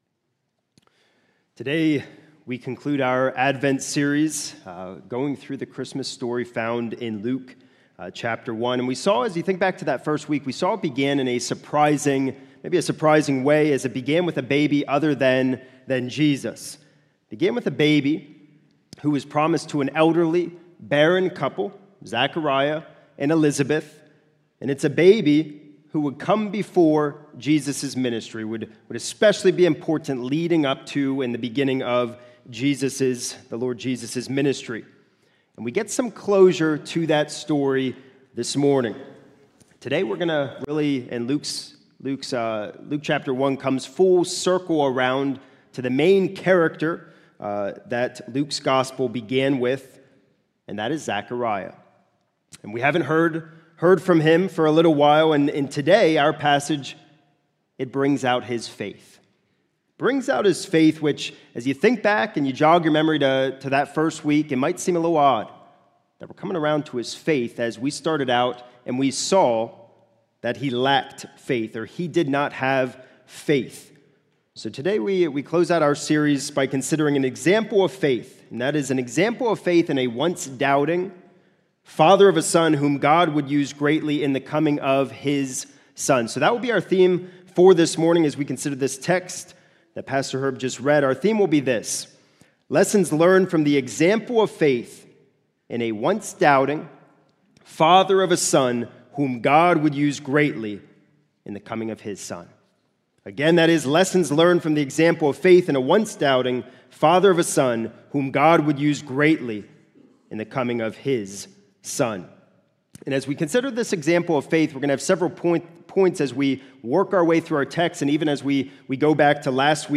It is taken from Luke 1:57-80. This sermon includes references to: